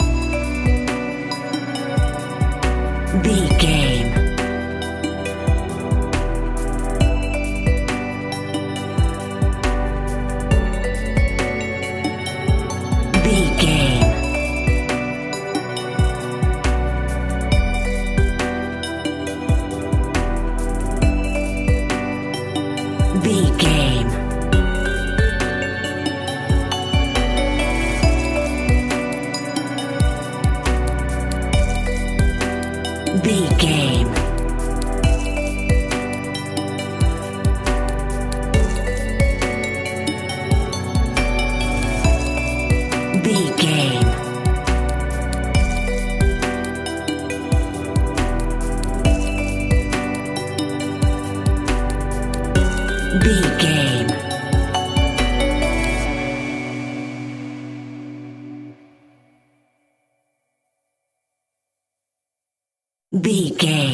Aeolian/Minor
groovy
dreamy
smooth
drum machine
synthesiser
synth bass